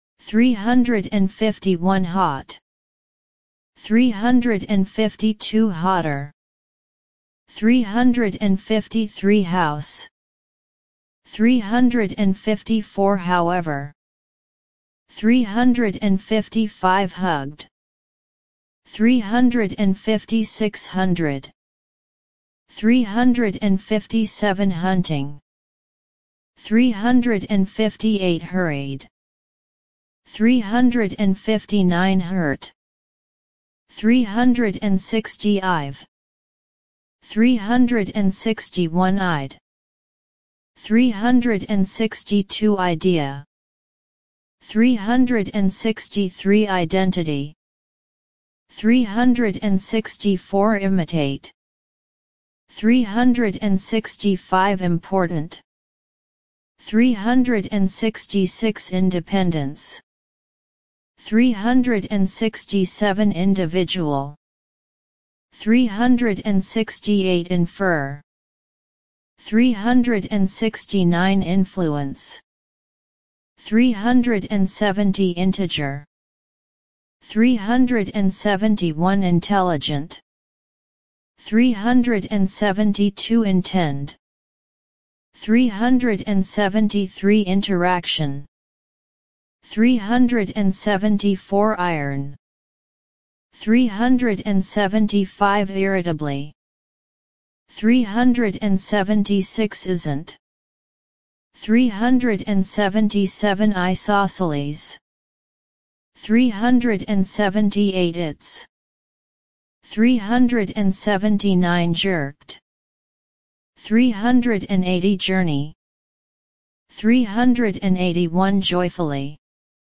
351 – 400 Listen and Repeat